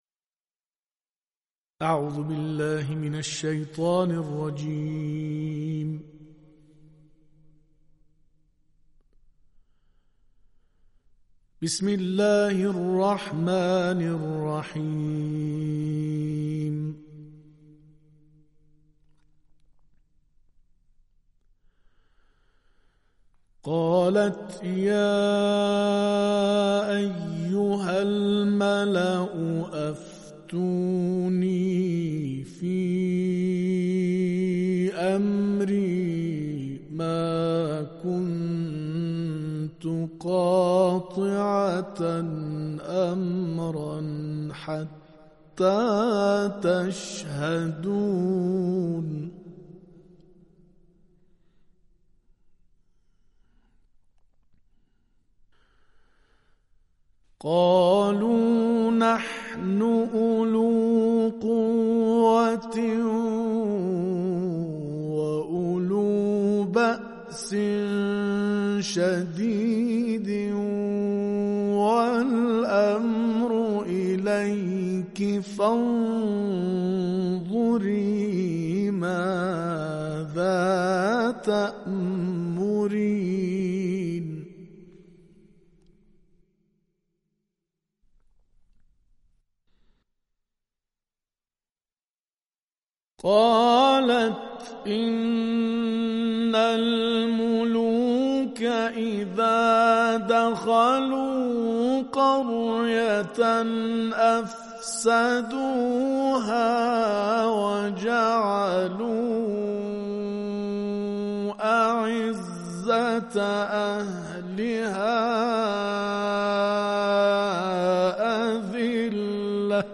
مدت زمان این تلاوت استودیویی 20 دقیقه است.